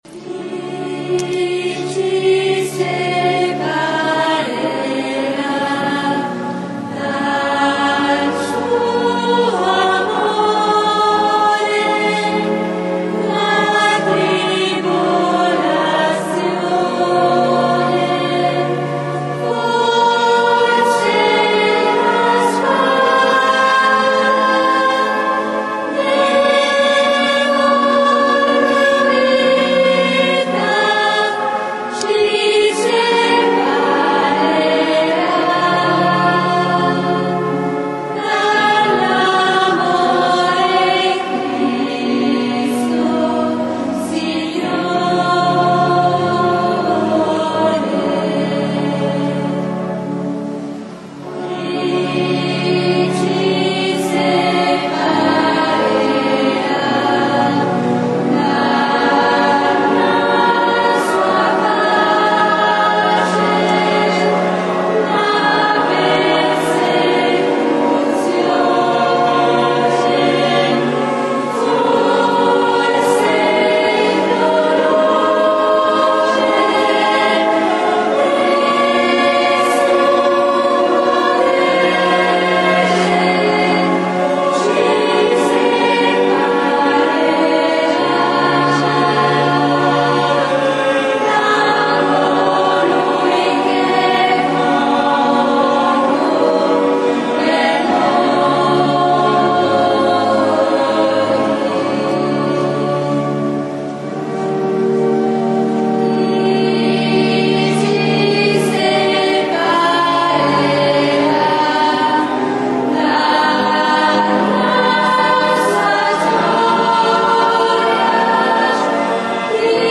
canto: Chi ci separerà